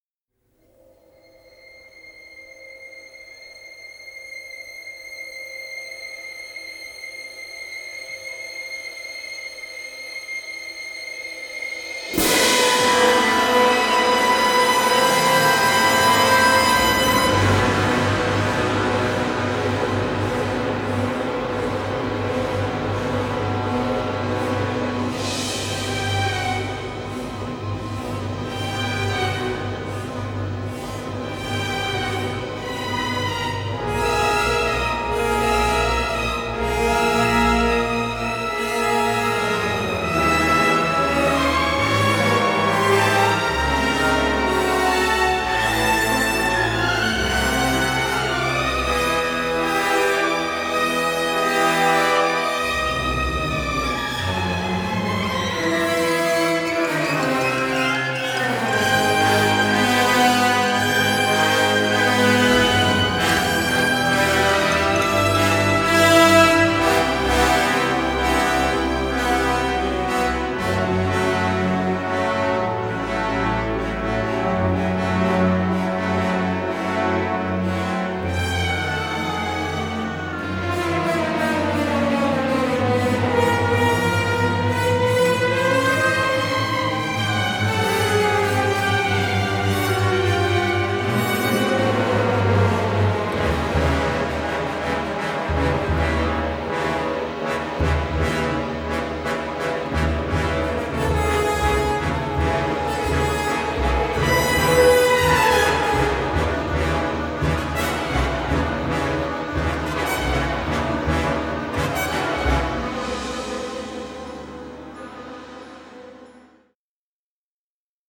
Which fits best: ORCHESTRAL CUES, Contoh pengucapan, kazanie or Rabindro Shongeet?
ORCHESTRAL CUES